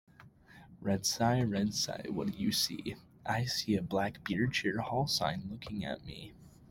A repetitive, rhythmic chant follows Red Cy as it spots different colored campus sights, ending with the Cyclones seeing them all looking back.